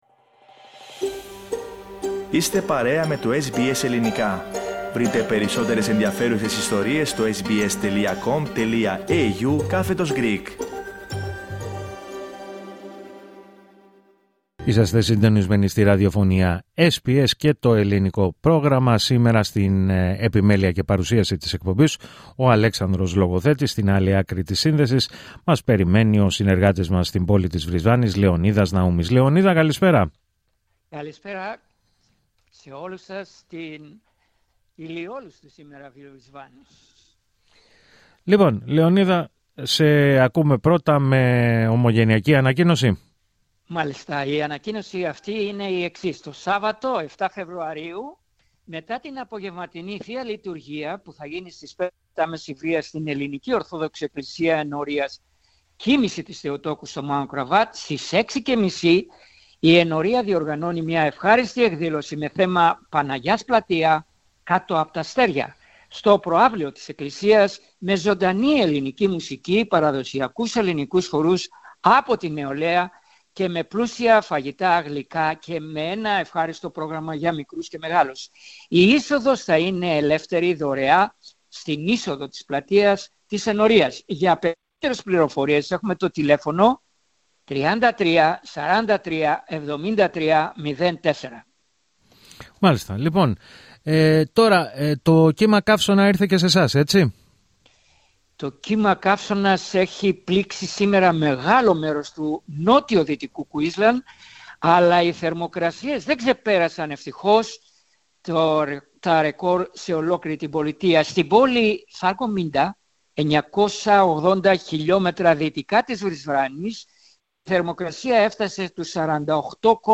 Ακούστε την εβδομαδιαία ανταπόκριση από την Βρισβάνη